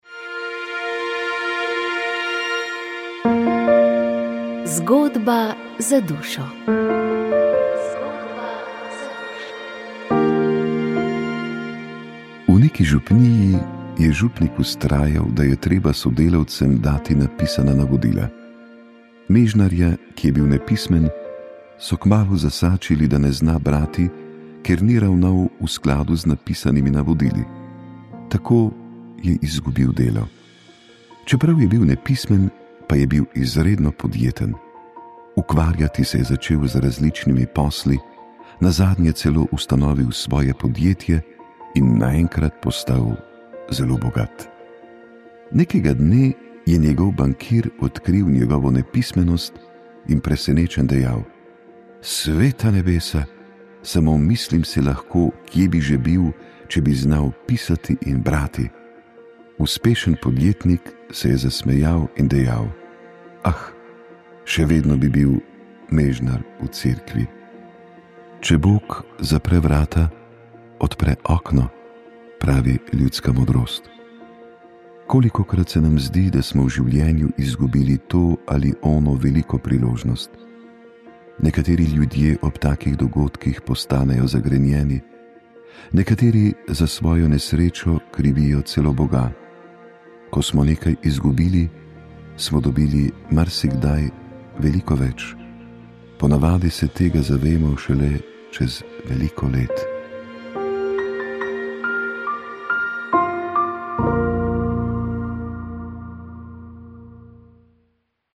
Tako smo izbirali tudi melodije v oddaji z zimzeleno glasbo.